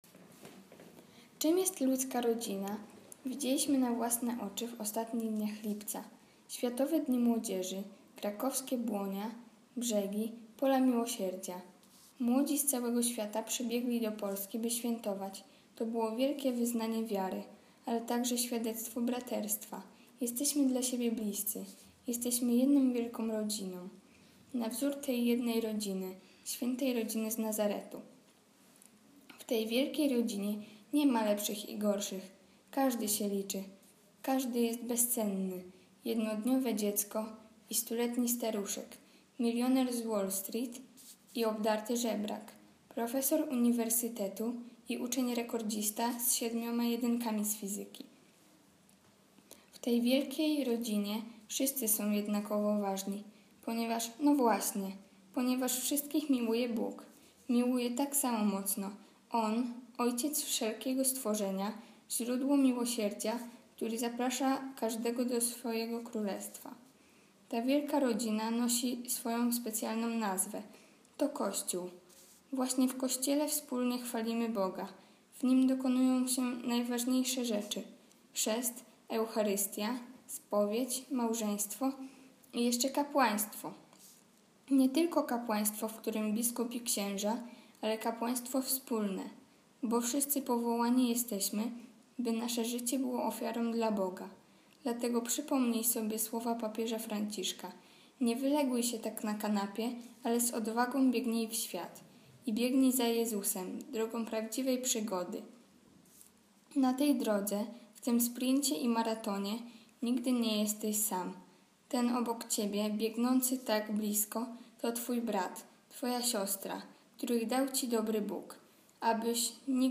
Komentarz do Ewangelii z dnia 20 września 2016 czyta